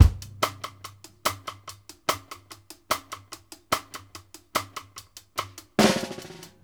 Index of /90_sSampleCDs/Sampleheads - New York City Drumworks VOL-1/Partition F/SP REGGAE 72